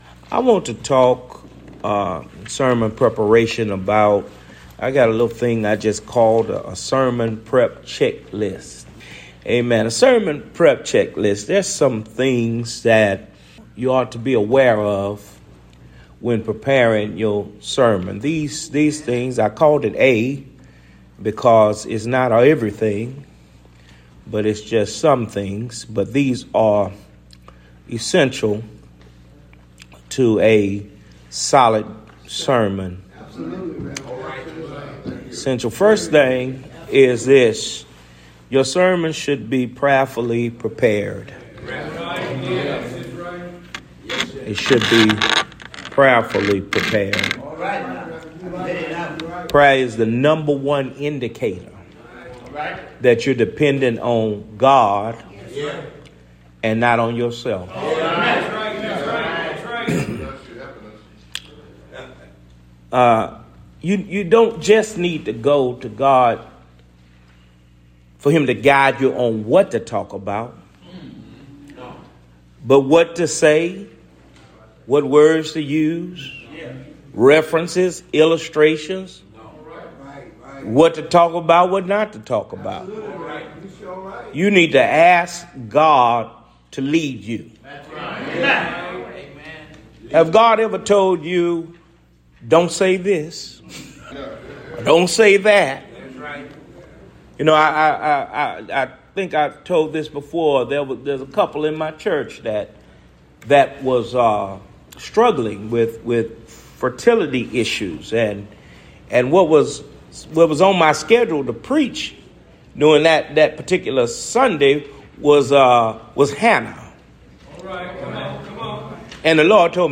10 minute lectures delivered @ Baptist Ministers of Houston & Vicinity